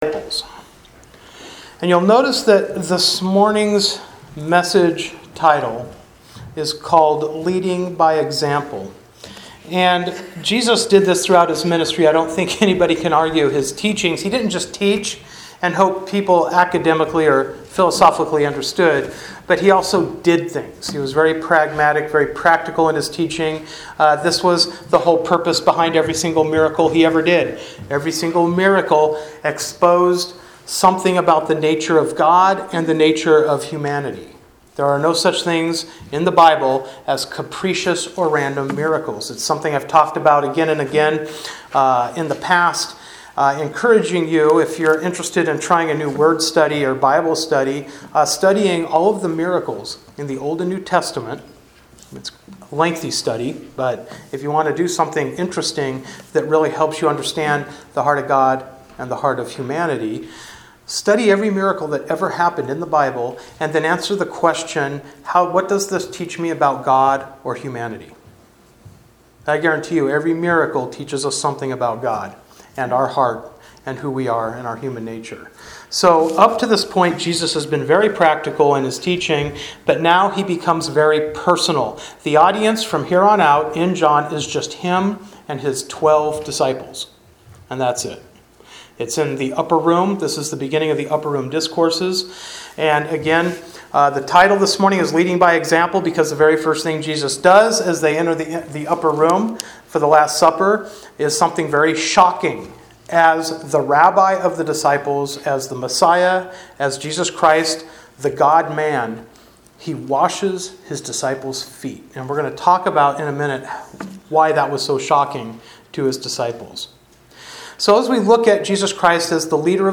John 13:1-20 Service Type: Sunday Morning Worship Bible Text